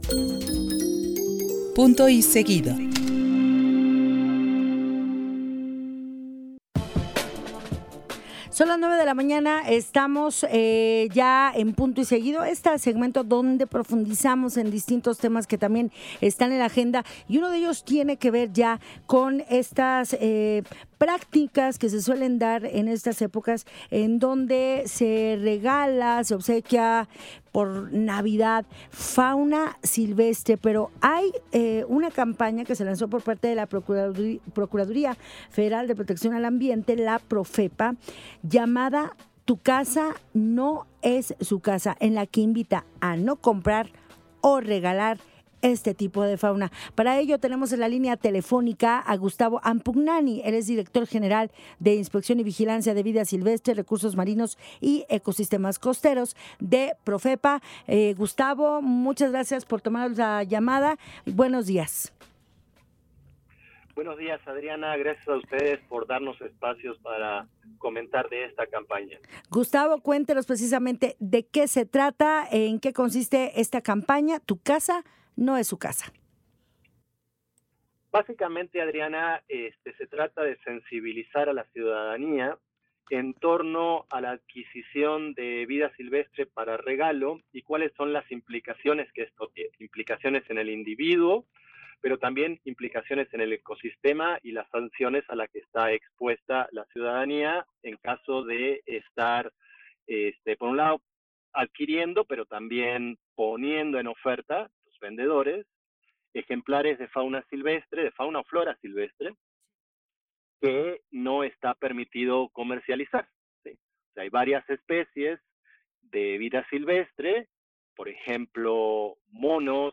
En entrevista, Gustavo Ampugnani, director de Vida Silvestre de la Profepa, advirtió sobre los riesgos del tráfico ilegal de animales.